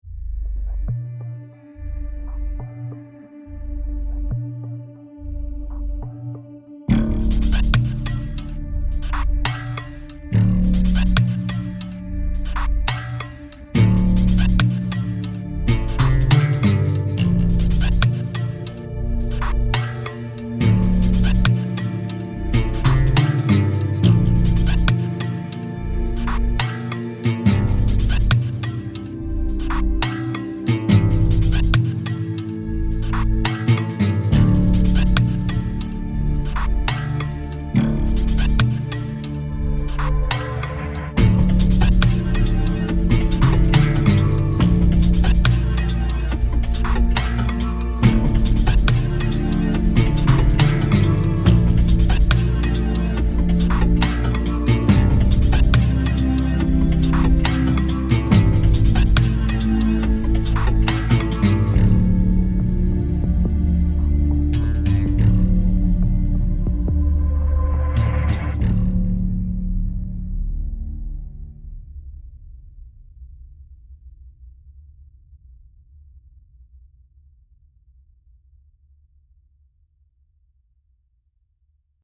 an array of synth and ambient sounds